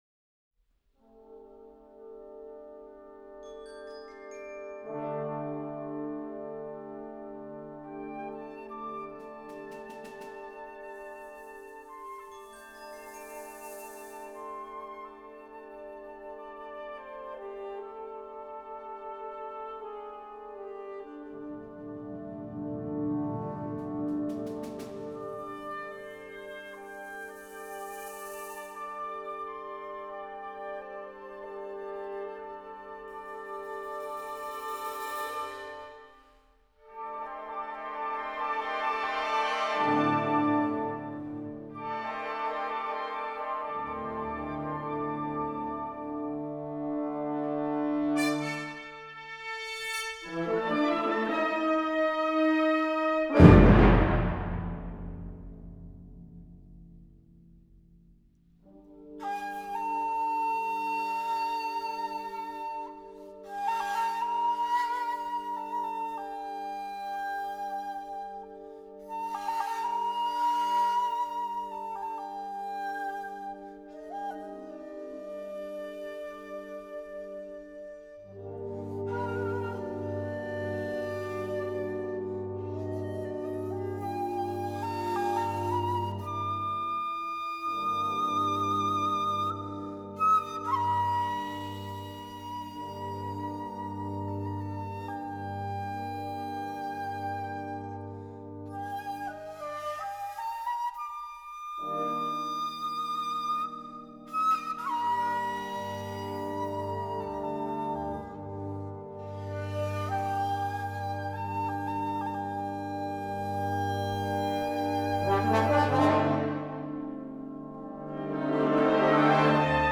Partitions pour ensemble flexible, 5-voix + percussion.